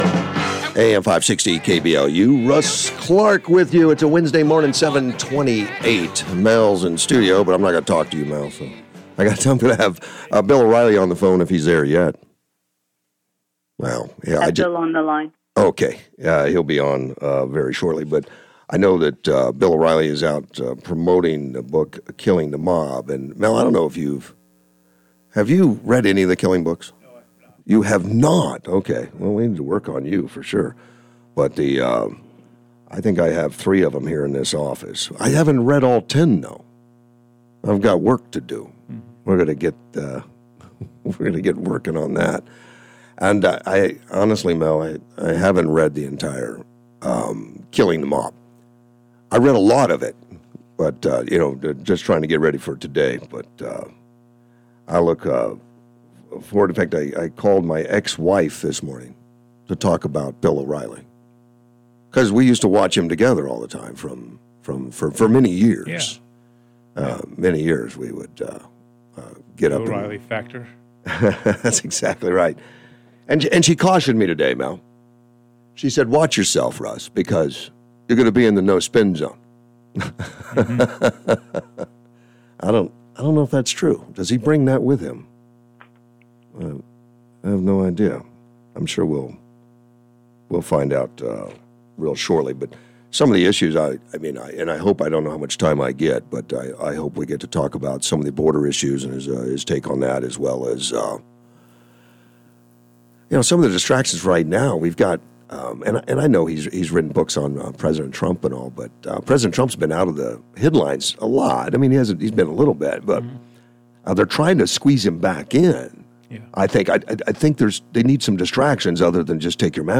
May 19, 2021 - Bill O'Reilly Interview